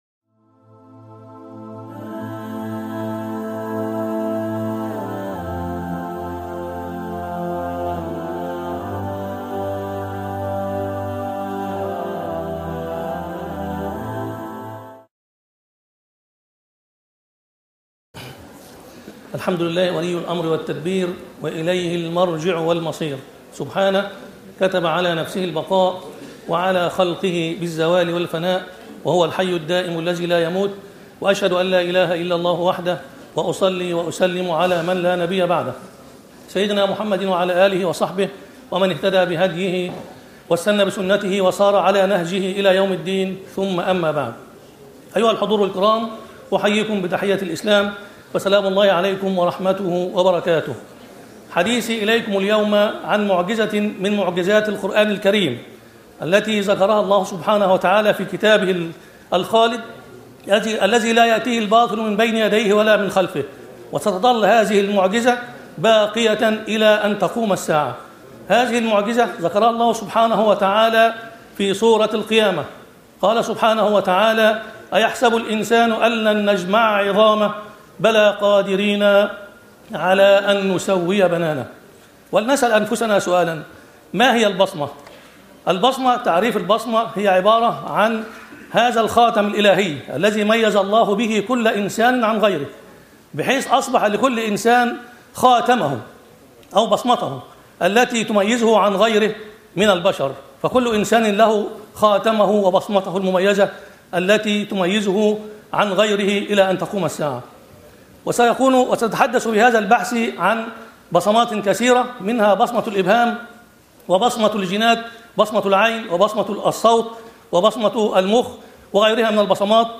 كلمة
بالمؤتمر السنوى العاشر للإعجاز العلمى بجامعة المنصورة 2018